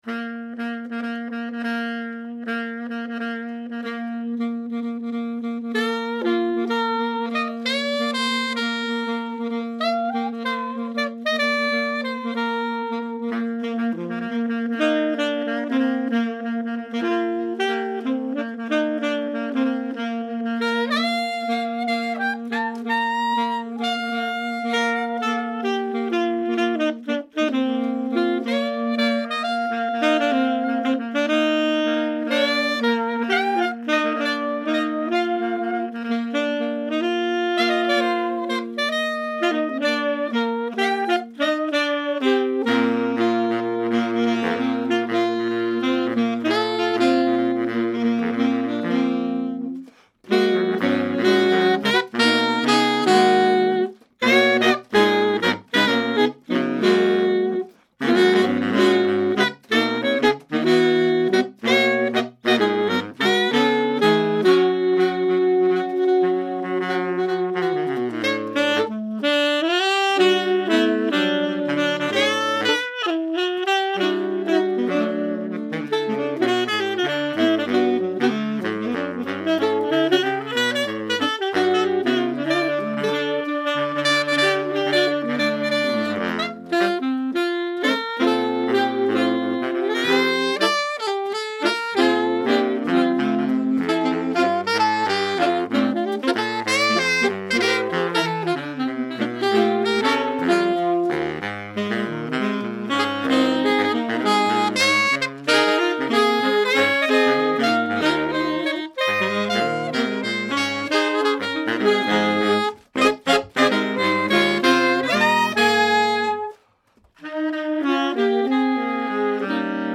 Voicing: 4 Sax